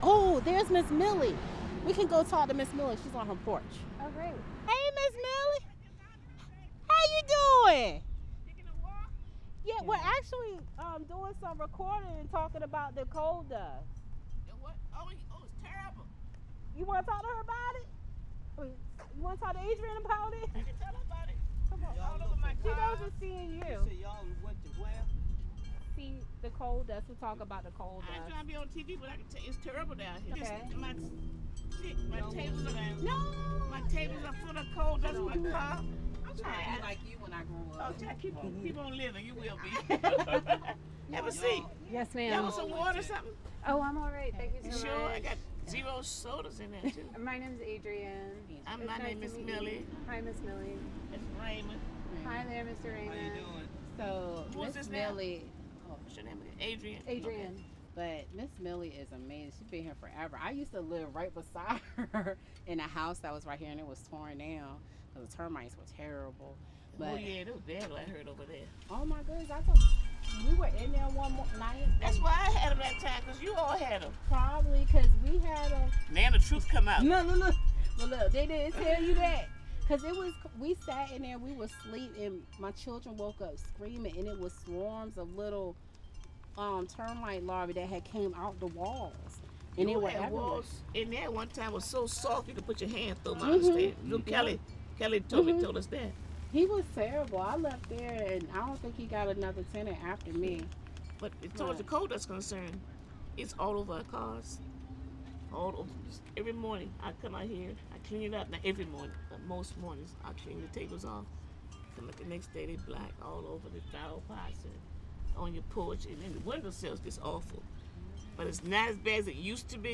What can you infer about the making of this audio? This is porch conversation that is densely packed with historical information, anecdotes and conjectures.